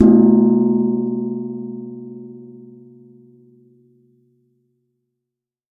Index of /90_sSampleCDs/Partition E/MIXED GONGS